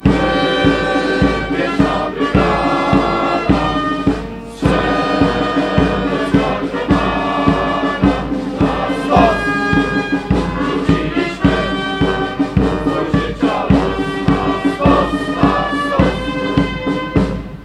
Uroczystości w deszczu
Obchody 228 rocznicy uchwalenia Konstytucji 3 maja zakończyło wspólne odśpiewanie Pieśni Legionów.